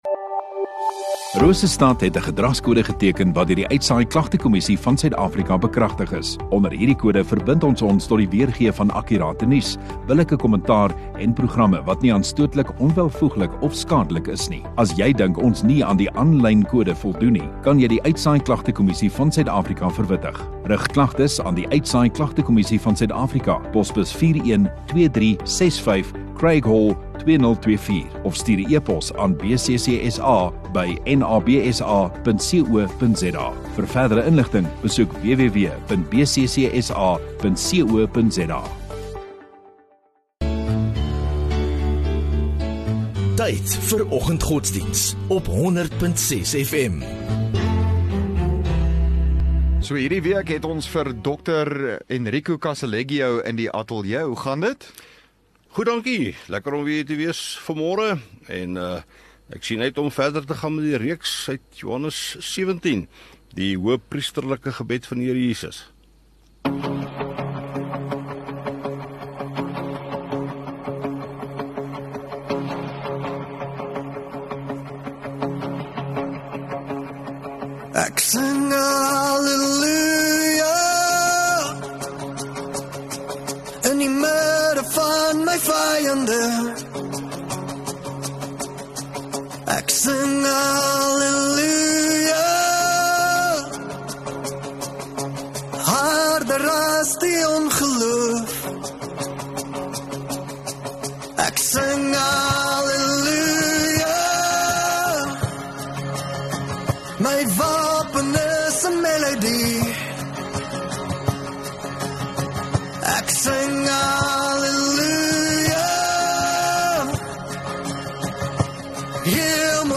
4 Feb Dinsdag Oggenddiens